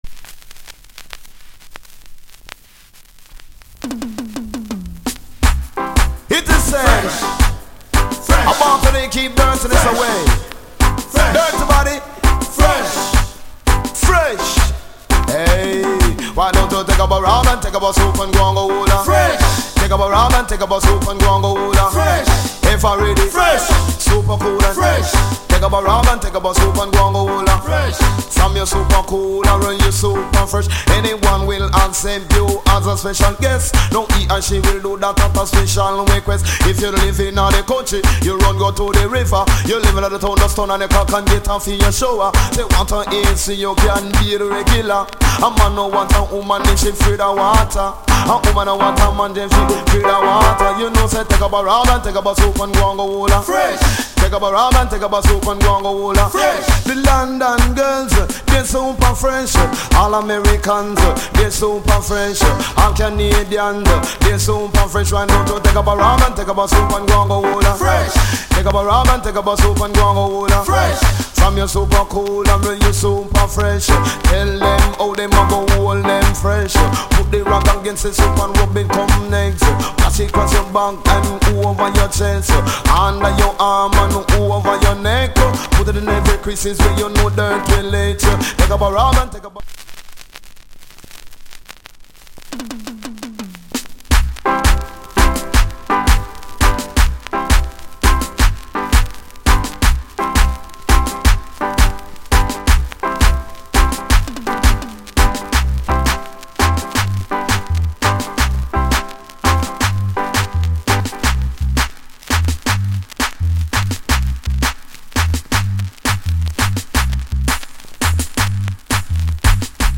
* '87 Big Hit **フレッシュ!のかけ声で大人気の一曲